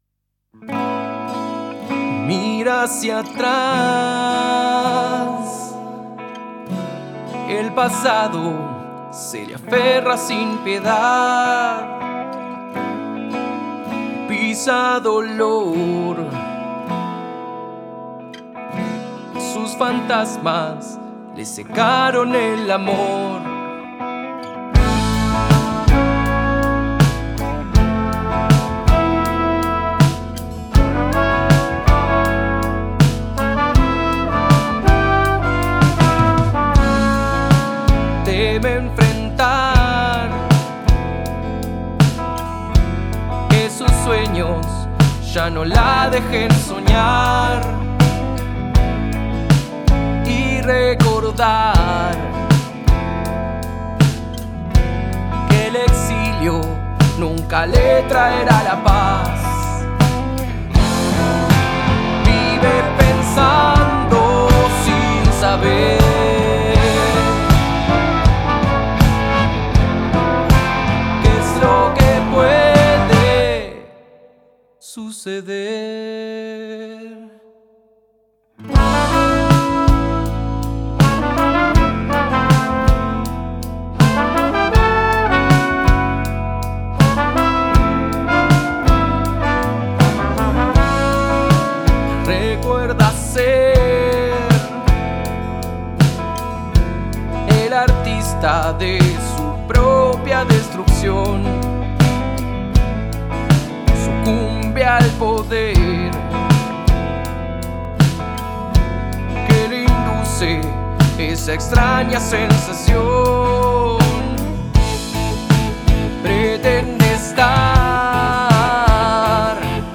guitarra acústica